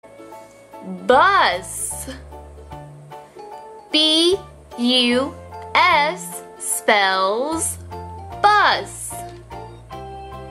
LAmfaZeEFOf_bus.mp3